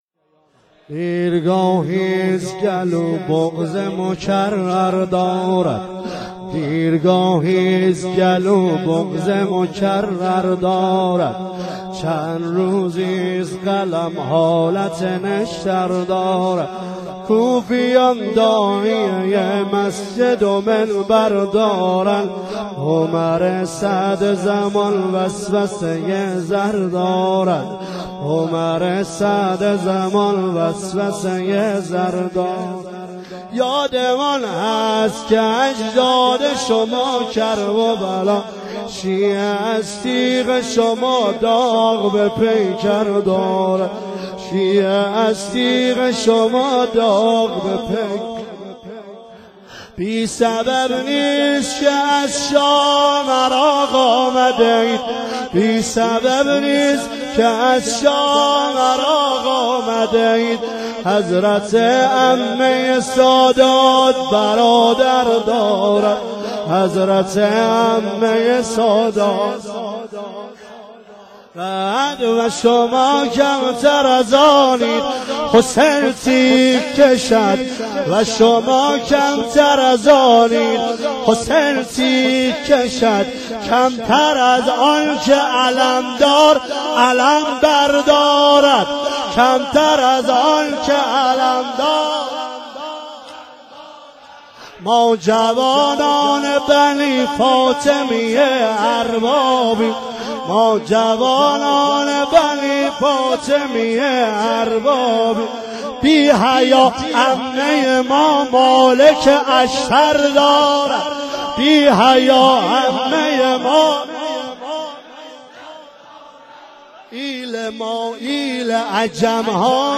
مداحی واحد